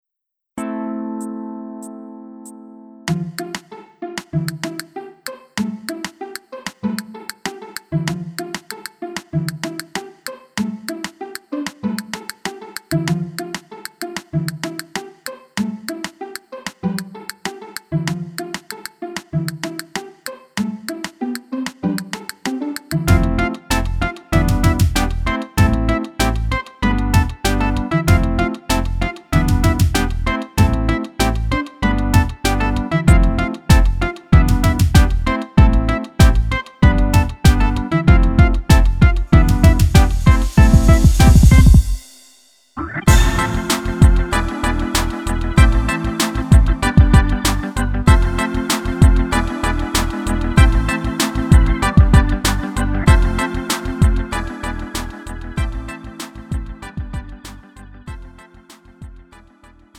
Lite MR은 저렴한 가격에 간단한 연습이나 취미용으로 활용할 수 있는 가벼운 반주입니다.
음정 원키
장르 가요